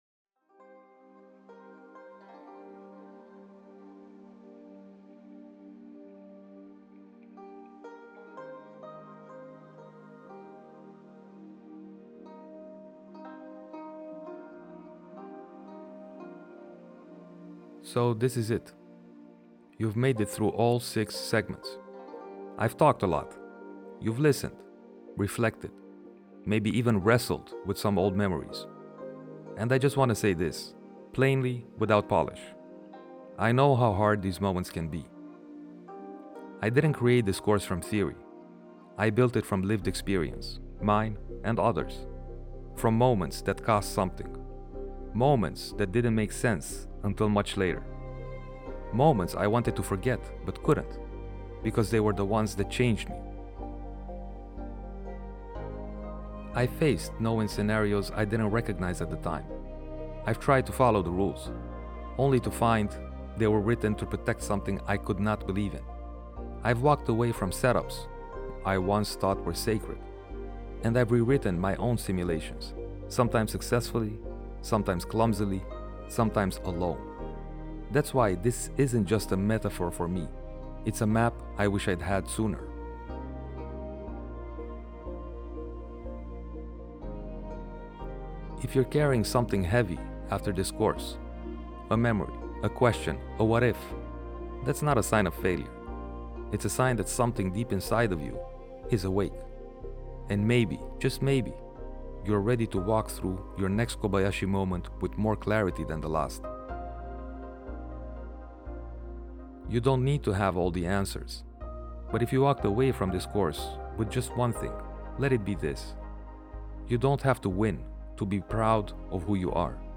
Just a quiet exhale after a long, honest journey.
In this final reflection, you’ll hear the voice behind the course; not as a teacher, but as a fellow traveler.